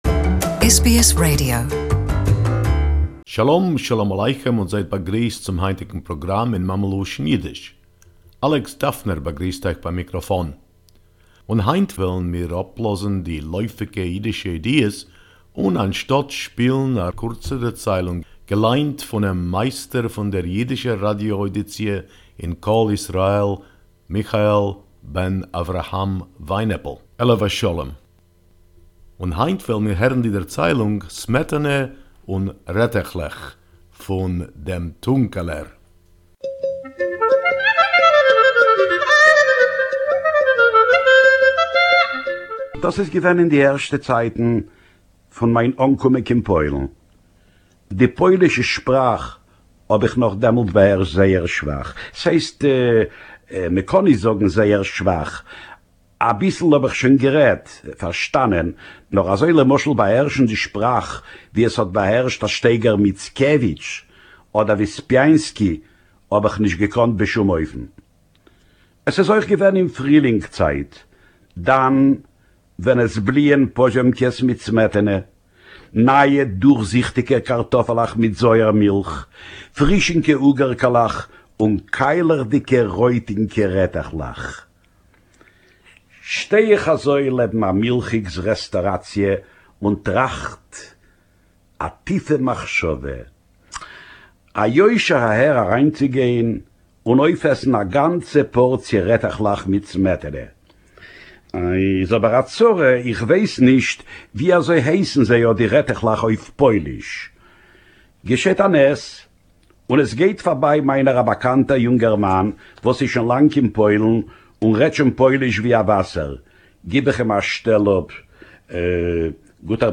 Yiddish story, Cream and Radishes